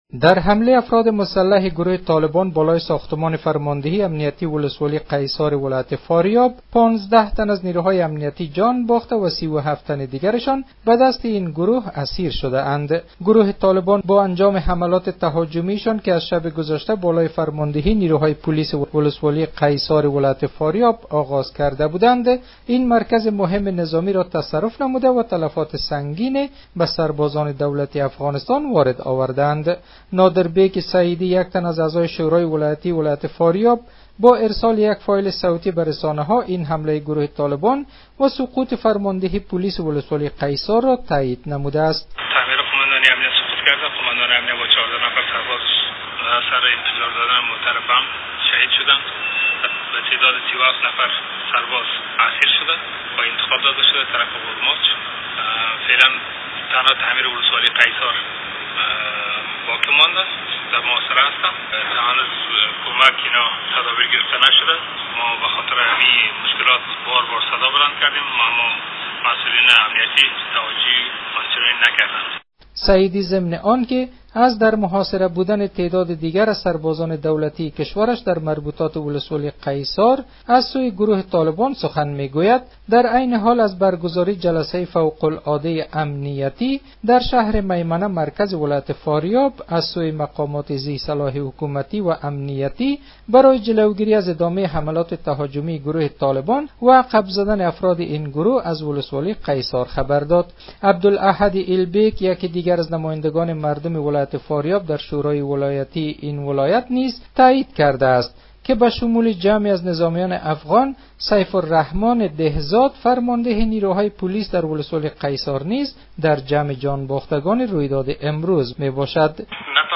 به گزارش رادیو دری به نقل از آوا، نادر سعیدی، عضو شورای ولایتی فاریاب امروز یکشنبه گفت، ساعت ۲۲ دیشب نیروهای طالبان به مقر فرماندهی پلیس ولسوالی قیصار حمله کردند.